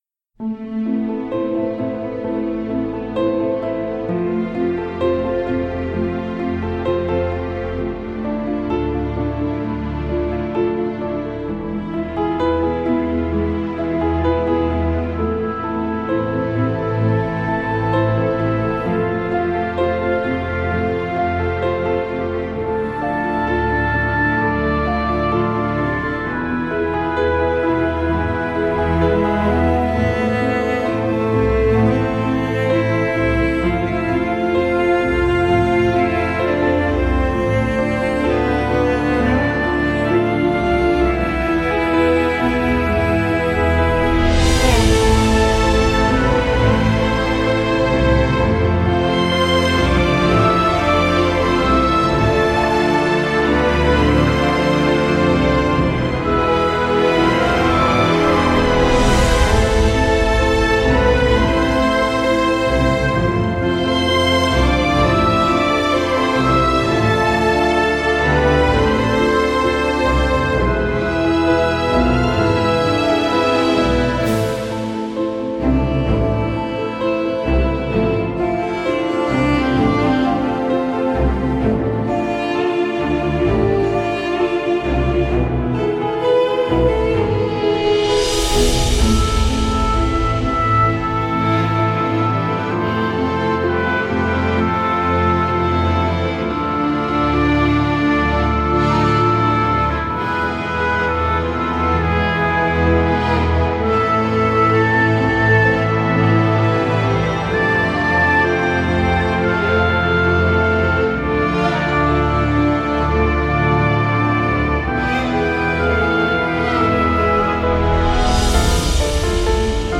Spiritual, Healing,Film Cue